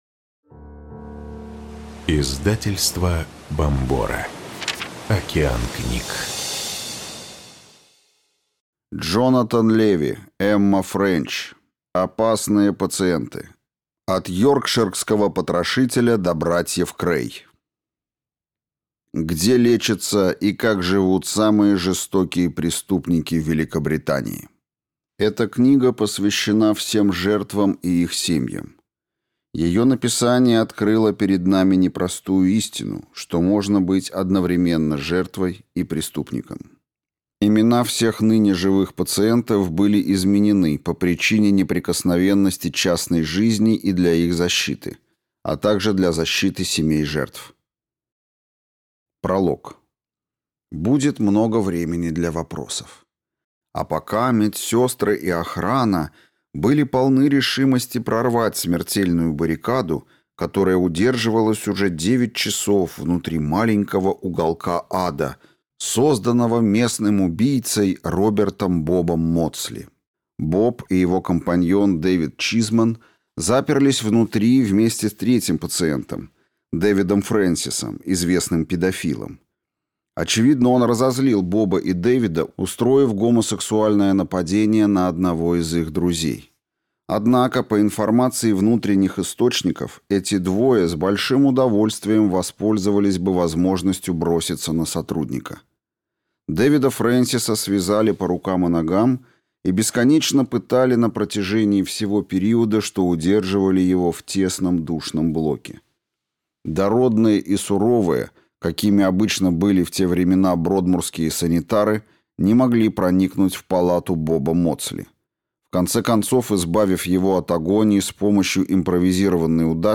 Аудиокнига Опасные пациенты. От Йоркширского потрошителя до братьев Крэй: где лечатся и как живут самые жестокие преступники Великобритании | Библиотека аудиокниг